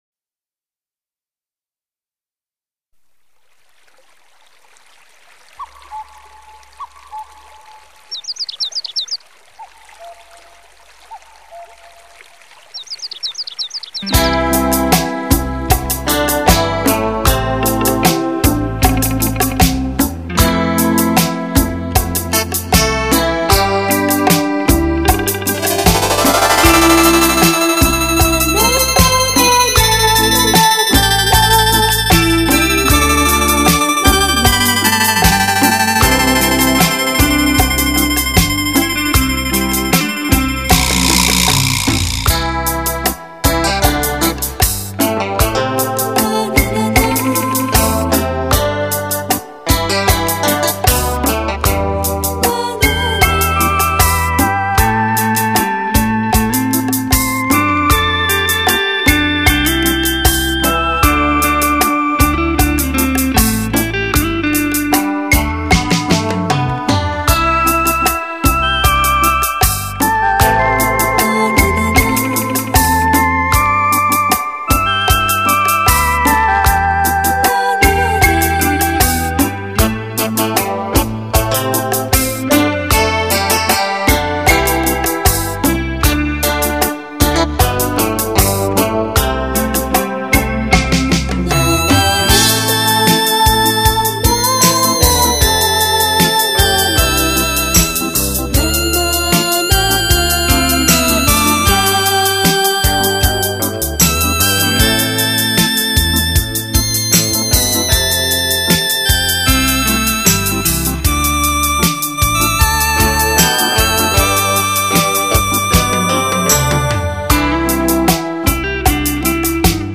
下来欣赏一下环绕音乐，多谢分享
好听优美的旋律支持